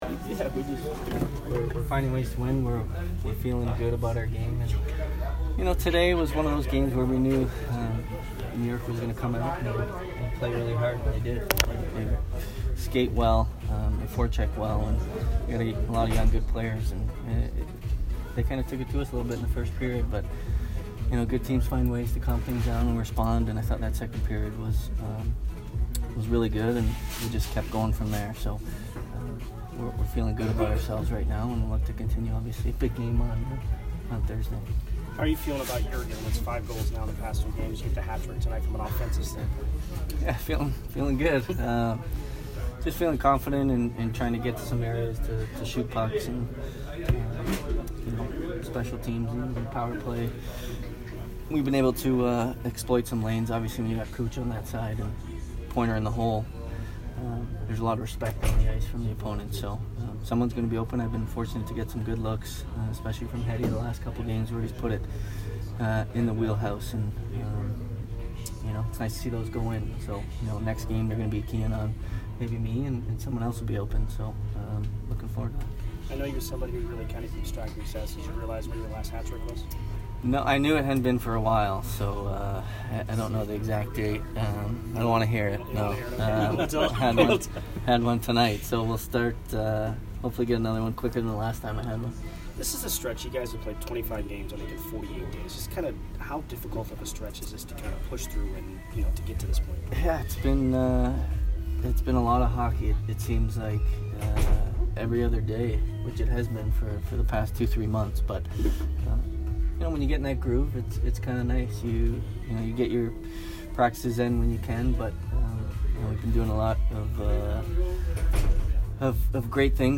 Steven Stamkos post-game 12/10